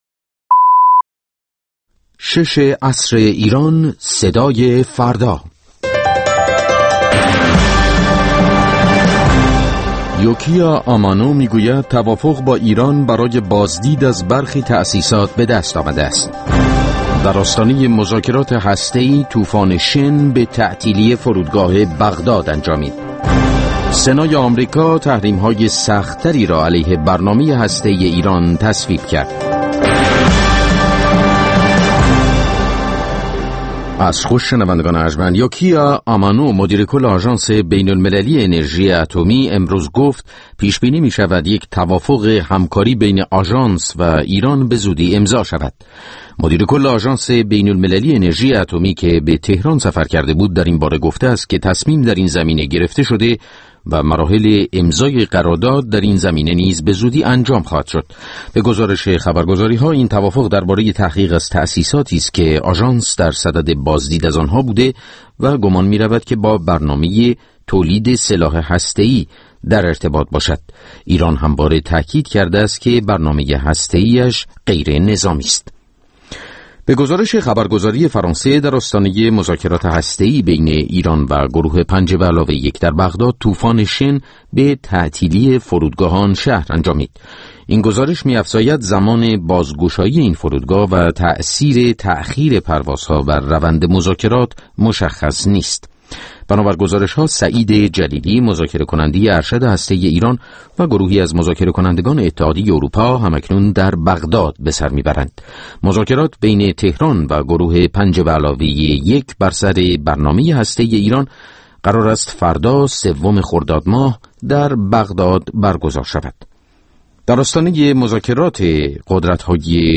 مستند رادیویی «انفرادی» هر هفته سه‌شنبه‌ها در ساعت ۱۸ به وقت ایران پخش می‌شود و روزهای پنجپنج‌شنبه ساعت ۱۴، روزهای جمعه ساعت ۹ صبح و یکشنبه‌ها ساعت ۲۳ به وقت ایران، تکرار می‌شود.